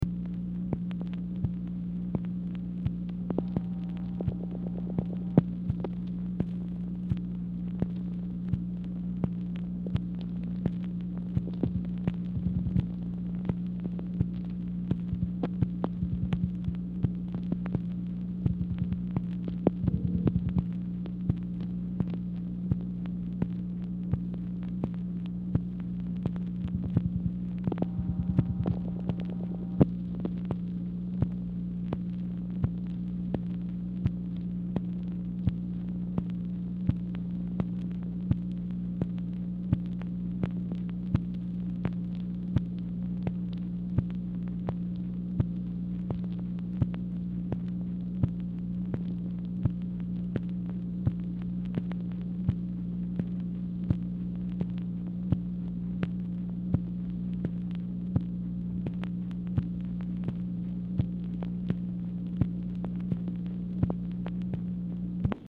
Telephone conversation # 10420, sound recording, MACHINE NOISE, 7/22/1966, time unknown | Discover LBJ
Format Dictation belt
Specific Item Type Telephone conversation